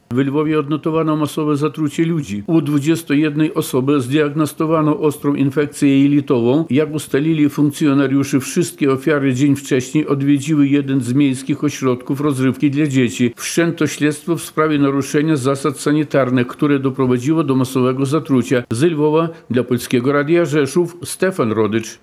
Ze Lwowa dla Polskiego Radia Rzeszów